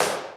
Claps
TS - CLAP (5).wav